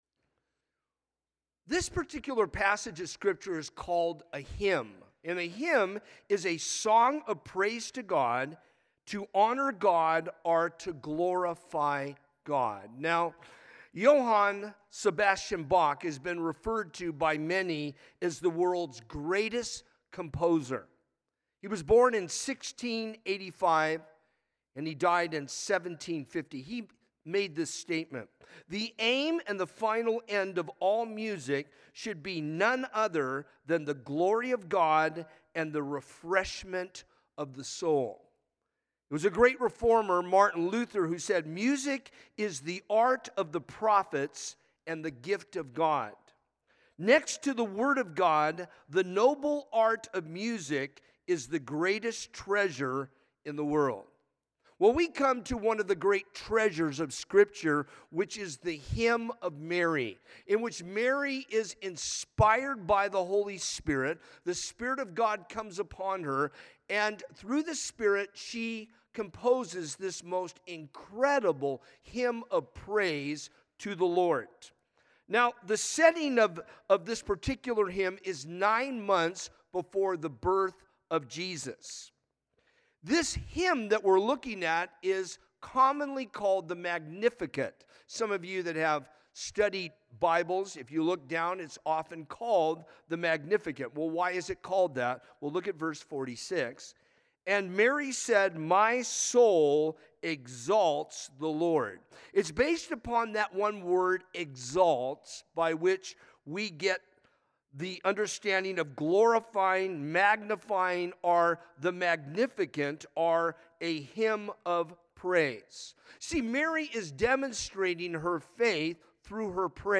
A message from the series "Christmas Eve - 18:00." No Birth Like The Birth Of Jesus, When God Took On Human Flesh And Became One Of Us To Save Us